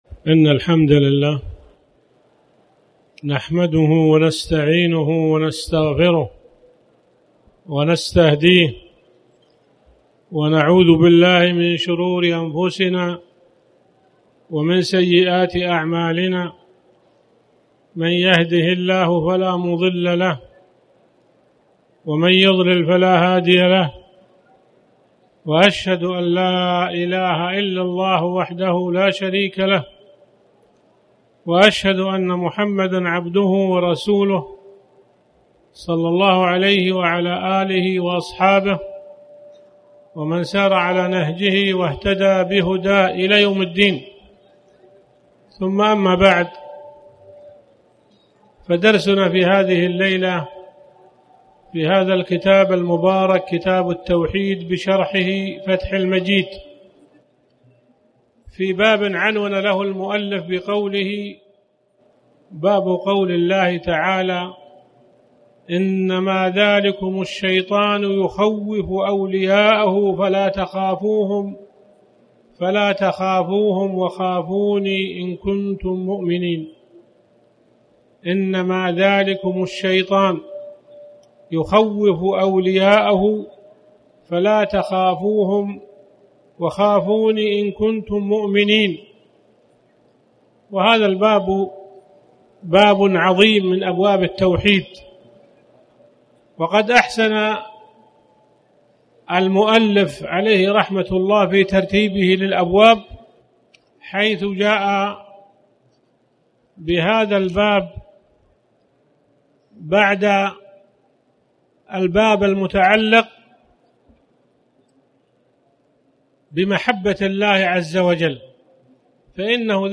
تاريخ النشر ٦ محرم ١٤٣٩ هـ المكان: المسجد الحرام الشيخ